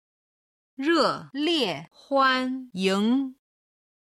01-reliehuanying.mp3